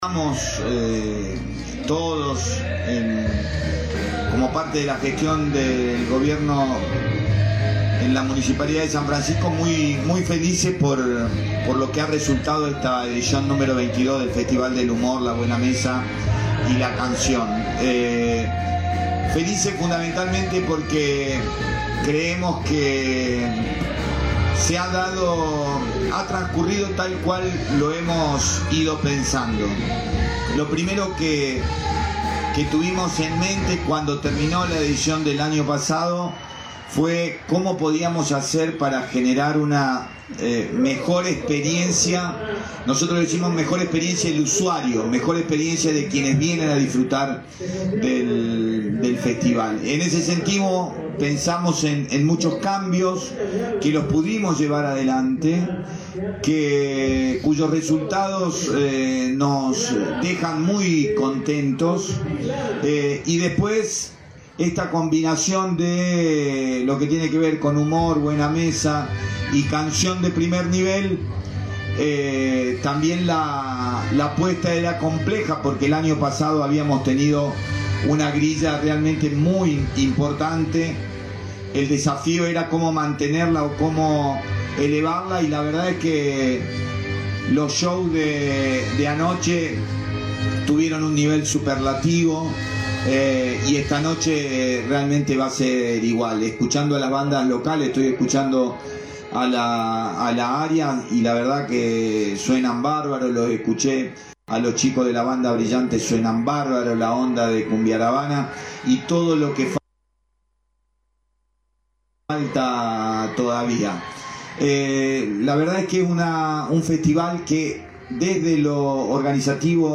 En conferencia de prensa, el intendente Damián Bernarte elogió la organización y las innovaciones del Festival del Humor, la Buena Mesa y la Canción 2025, destacando su crecimiento constante. También respondió a las críticas por la inversión en espectáculos públicos.